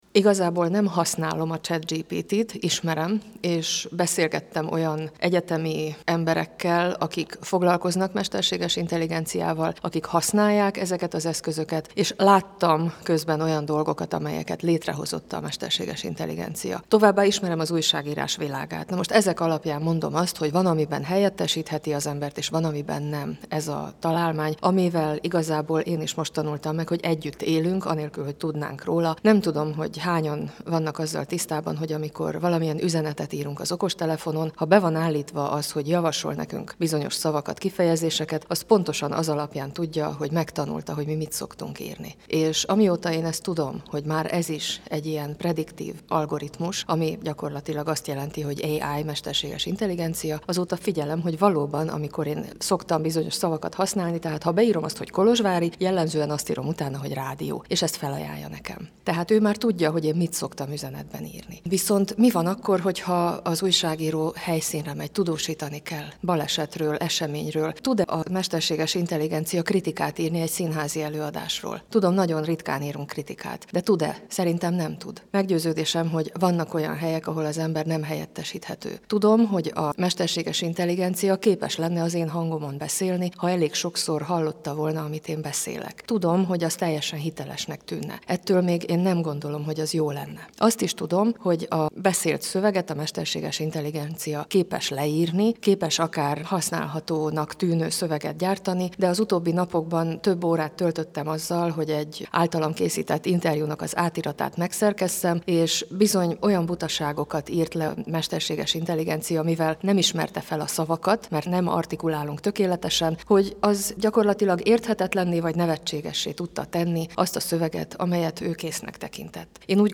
Kerekasztal-beszélgetés a politikum és a sajtó között